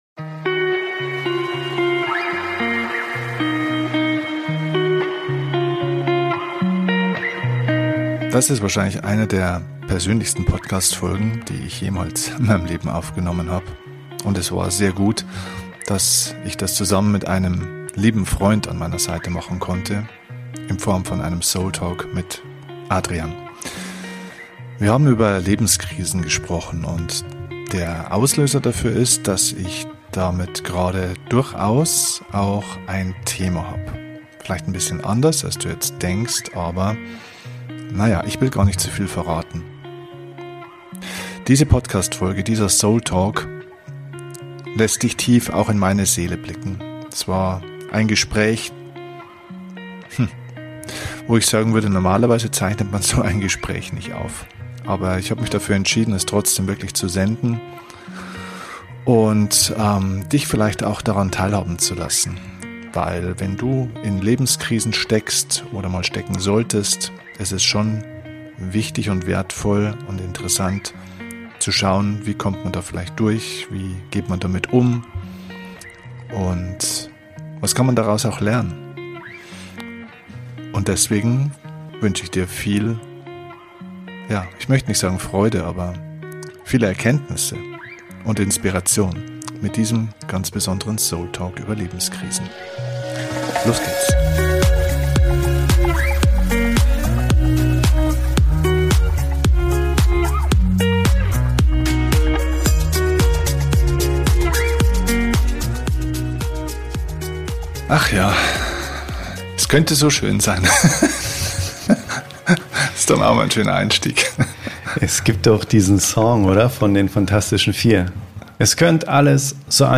Wie immer bei diesem Format: kein Skript, nur zwei Freunde die miteinander reden.